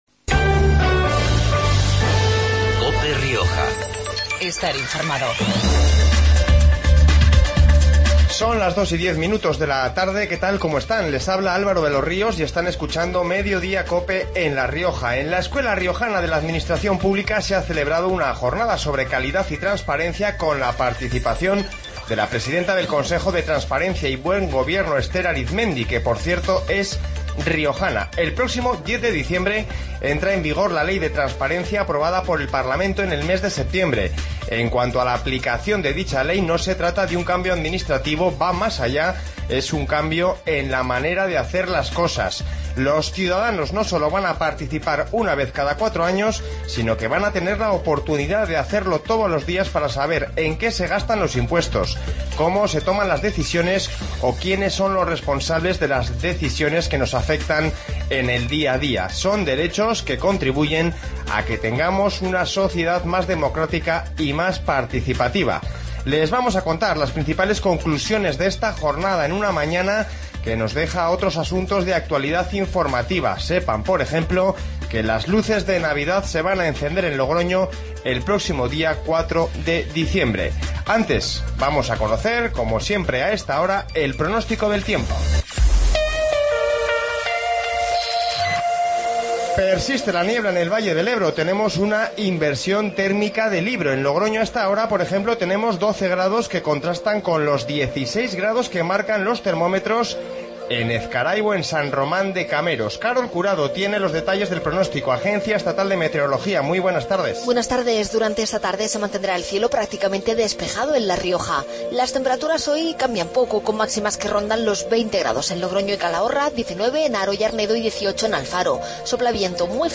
Informativo Mediodia en La Rioja 11-11-15